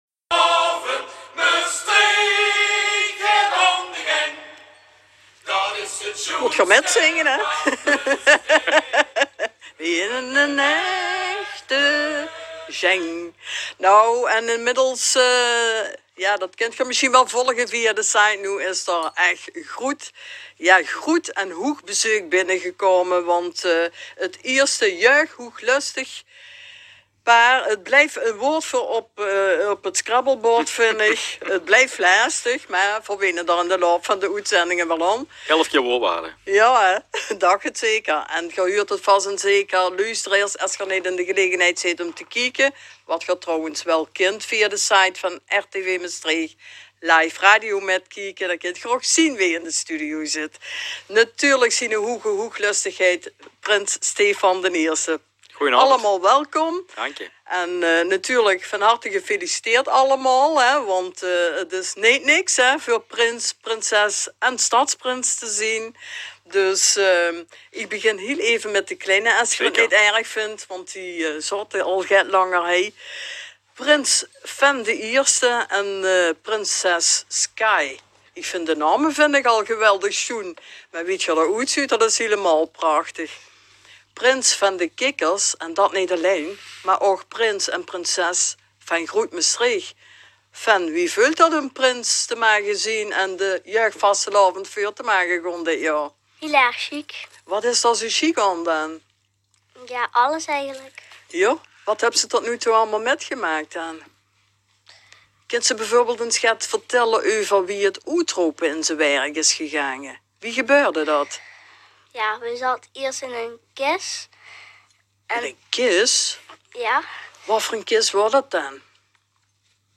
met rtv mestreech | radio programma de störm kump